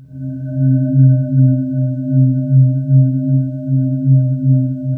Index of /90_sSampleCDs/USB Soundscan vol.28 - Choir Acoustic & Synth [AKAI] 1CD/Partition D/05-SPECTRE